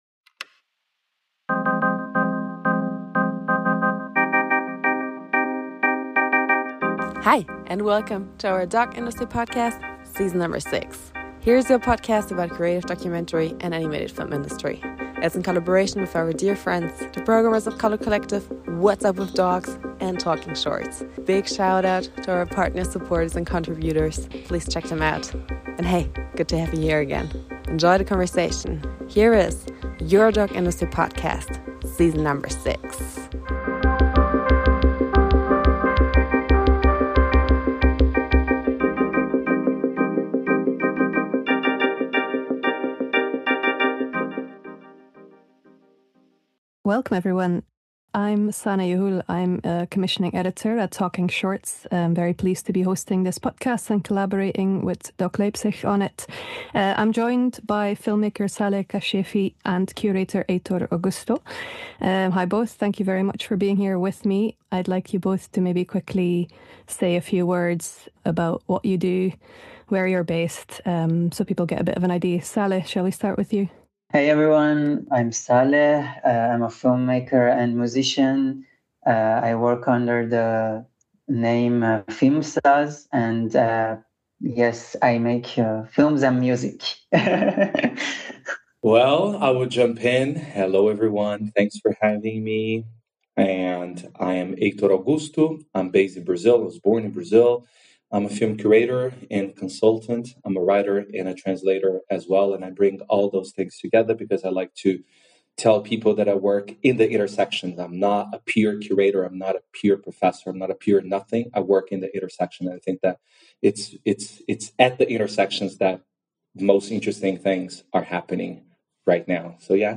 Between Co-Option and Solidarity: Conversation